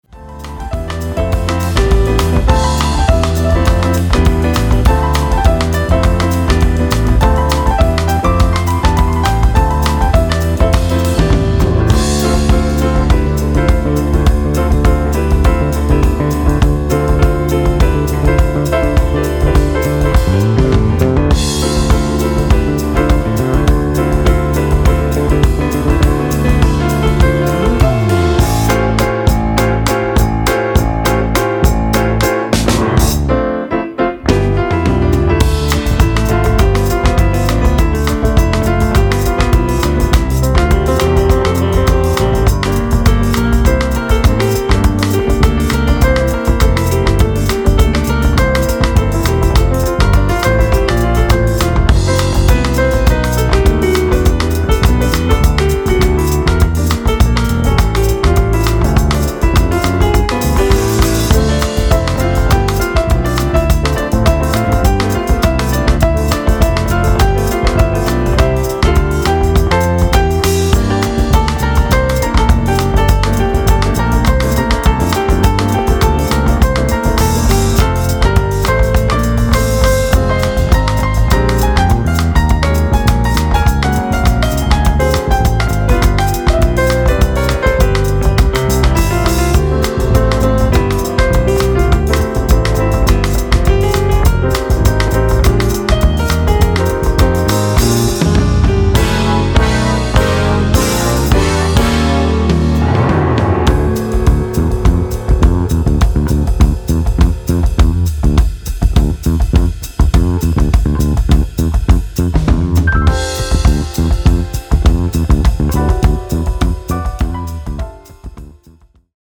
繊細なタッチと深みのある演奏とアレンジで、新たな命を吹き込みました。